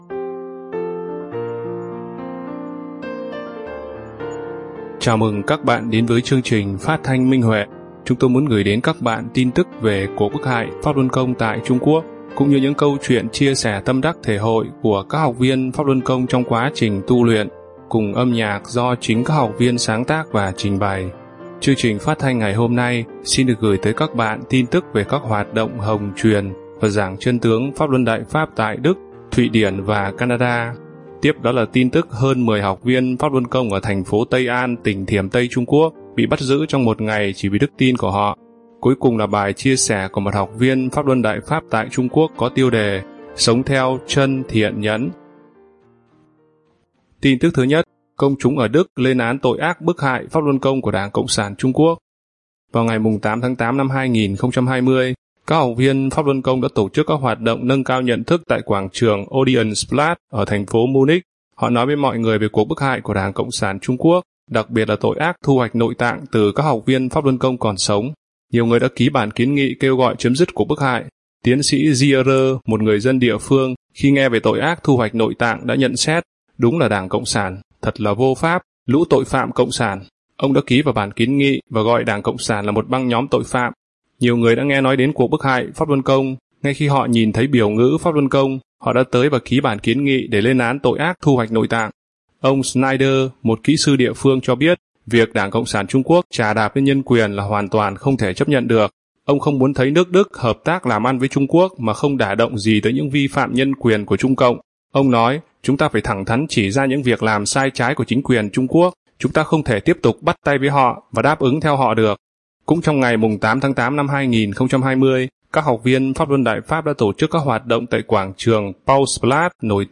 Chào mừng các bạn đến với chương trình phát thanh Minh Huệ. Chúng tôi muốn gửi đến các bạn tin tức về cuộc bức hại Pháp Luân Công tại Trung Quốc cũng như những câu chuyện chia sẻ tâm đắc thể hội của các học viên Pháp Luân Công trong quá trình tu luyện, cùng âm nhạc do chính các học viên sáng tác và trình bày.